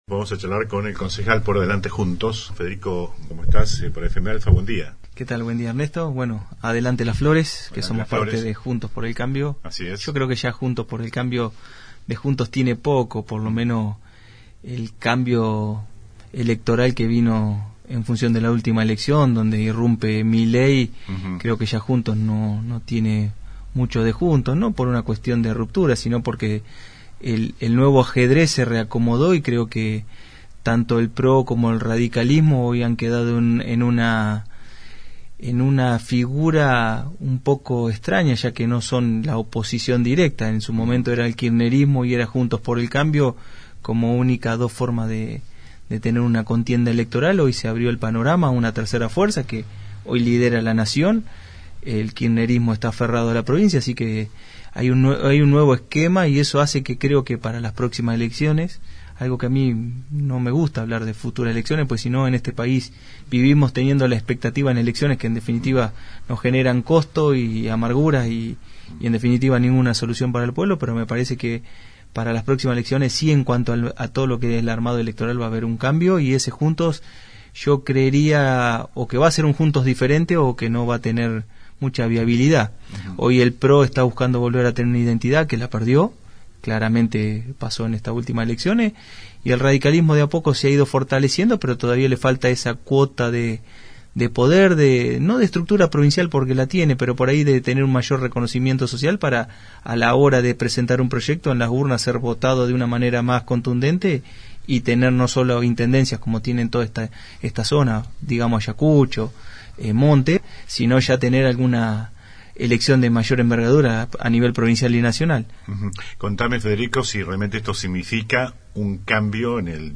AUDIO DE LA ENTREVISTA A DORRONSORO.